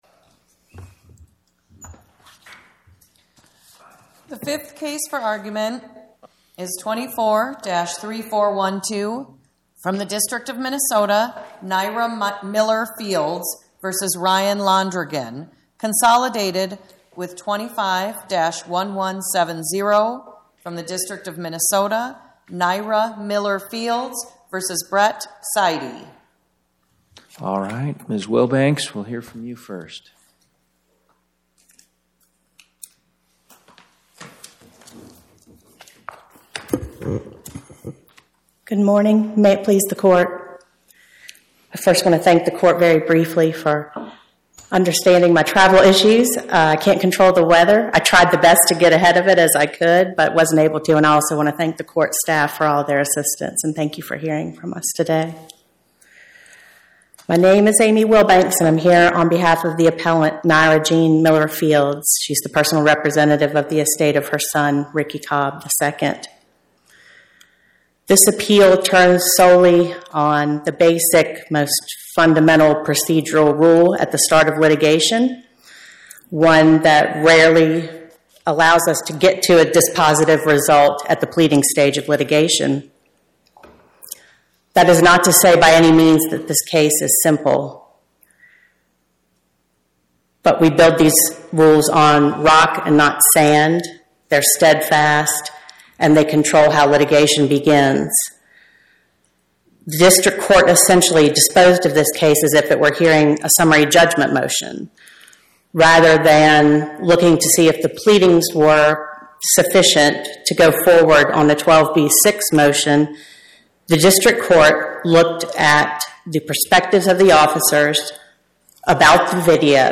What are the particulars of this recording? Oral argument argued before the Eighth Circuit U.S. Court of Appeals